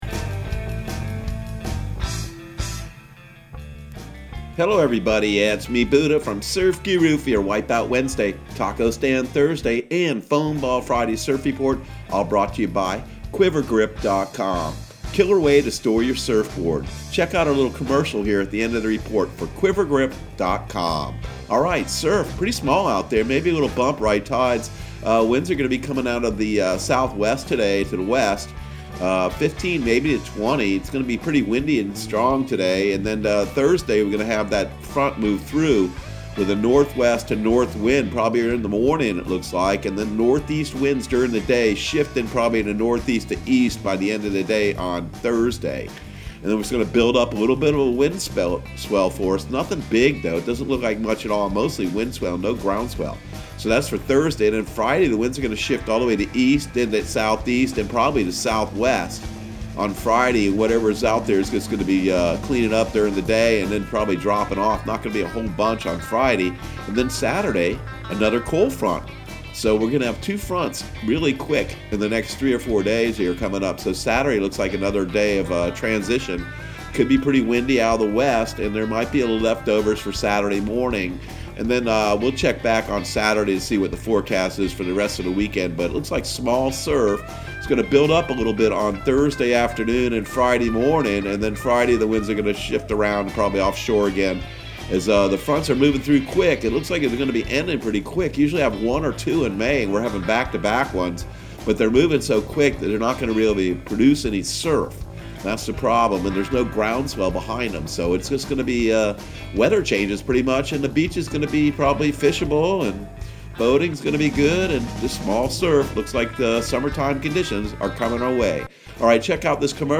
Surf Guru Surf Report and Forecast 05/06/2020 Audio surf report and surf forecast on May 06 for Central Florida and the Southeast.